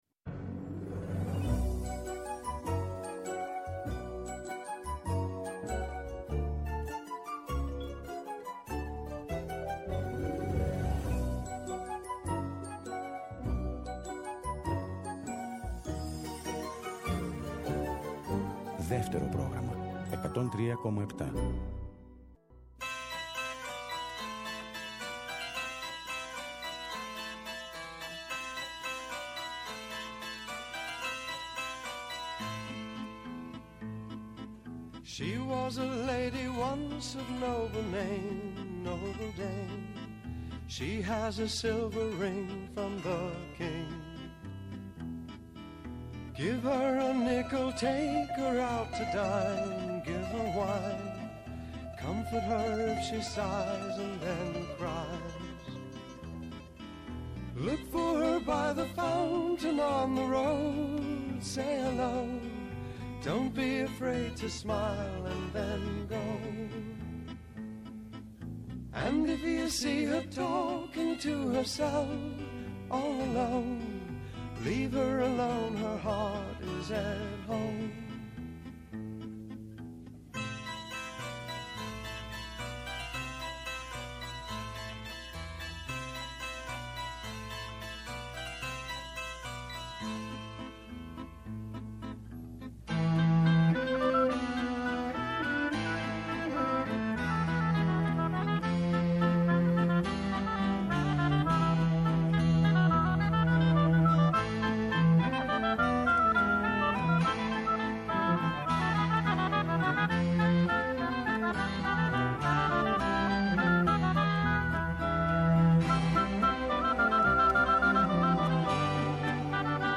παρουσιάζει μια μουσική -και όχι μόνο- αλληλουχία